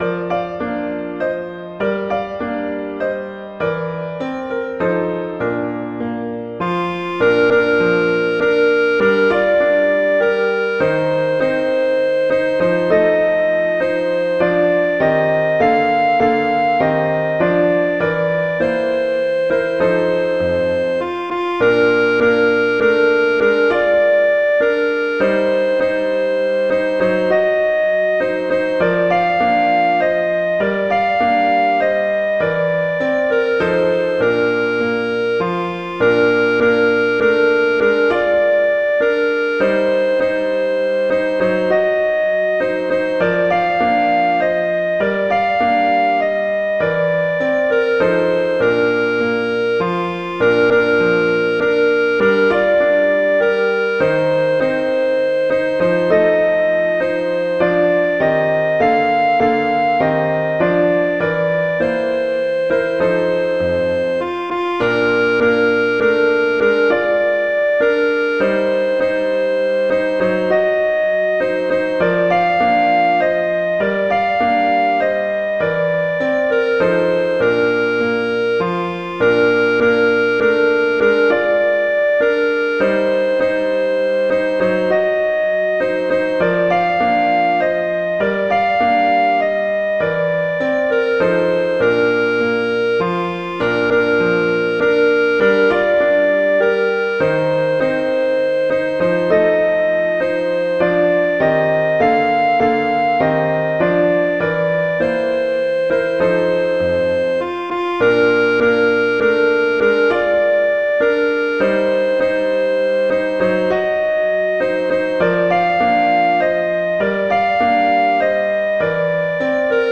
arrangements for clarinet and piano
traditional, irish, children
♩=60-120 BPM